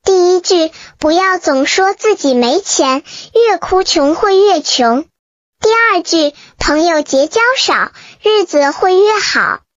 Voix captivante d'IA pour la narration d'histoires pour enfants
Découvrez une voix IA douce et expressive adaptée aux histoires du soir, aux livres audio pour enfants et aux expériences d'apprentissage interactives.
Synthèse vocale
Narration douce
Notre IA fournit un ton doux et animé qui imite la chaleur d'un parent ou d'un enseignant lisant à voix haute.